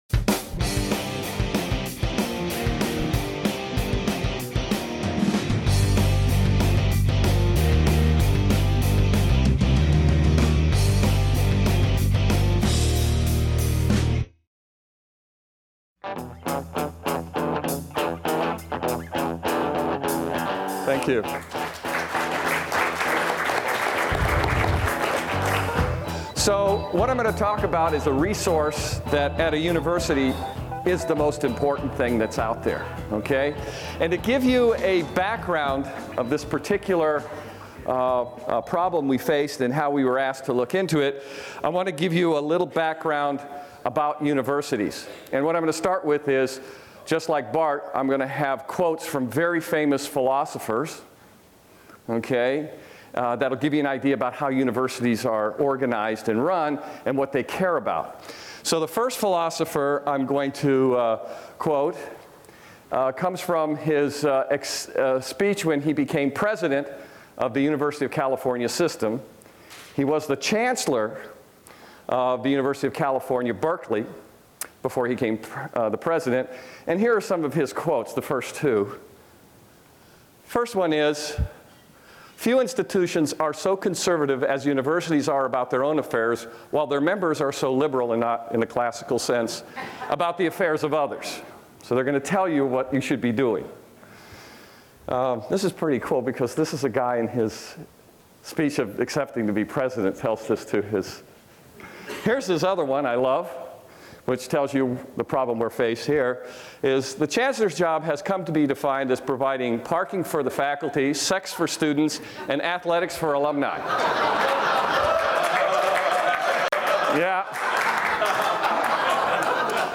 He told an audience at Reason Foundation's annual Reason Weekend what his solution was: Auction off the spaces.